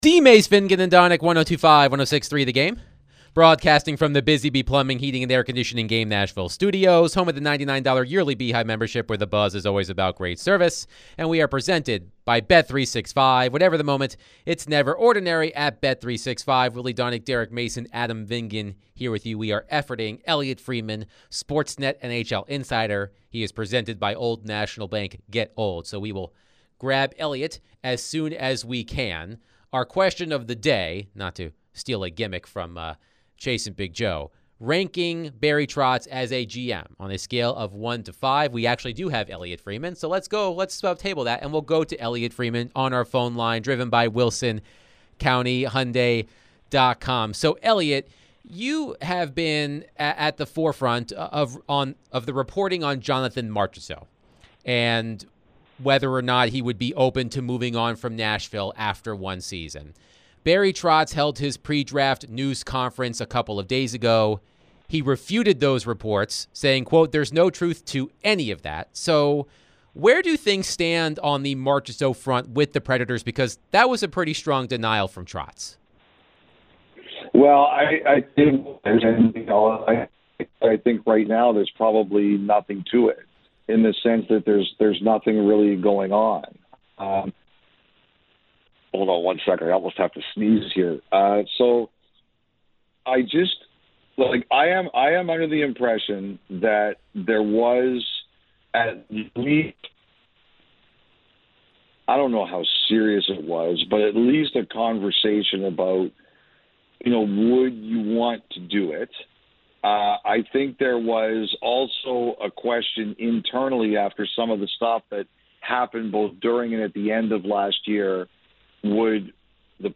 Sports Net NHL Insider Elliotte Friedman joins DVD to discuss Jonathan Marchessault, Preds, NHL Draft, NHL Off-season, and more.